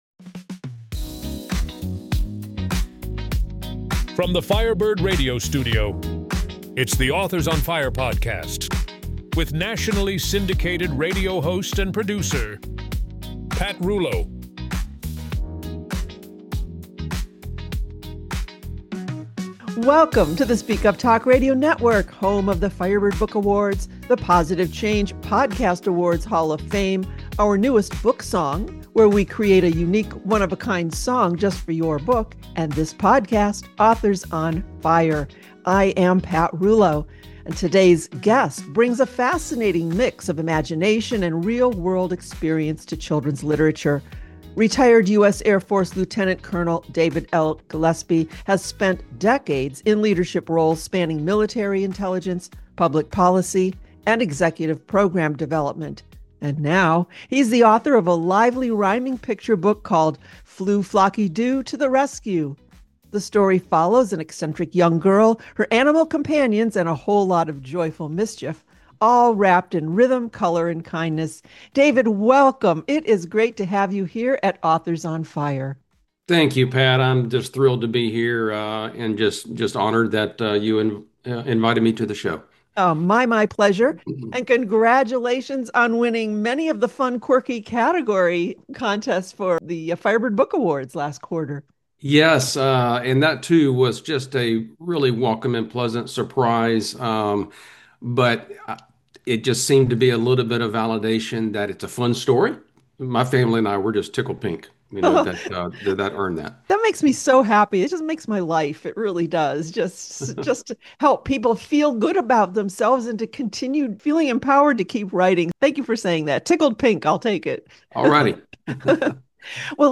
Author Interview